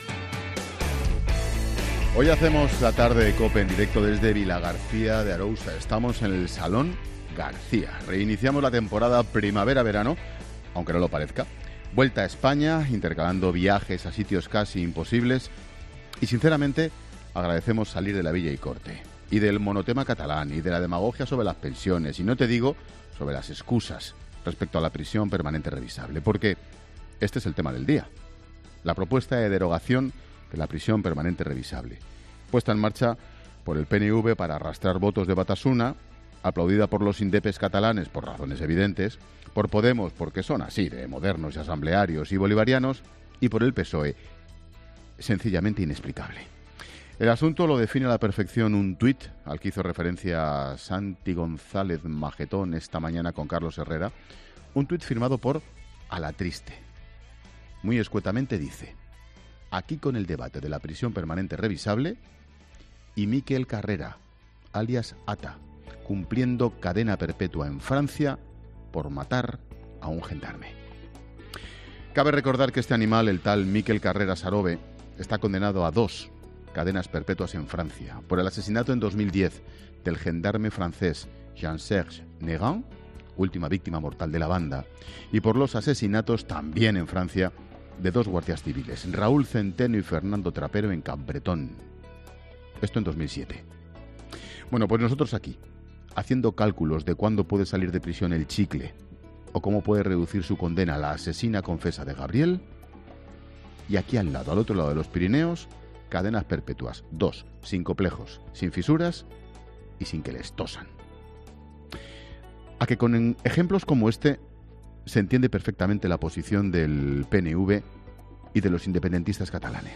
Monólogo de Expósito
Comentario de Ángel Expósito sobre la prisión permanente revisable.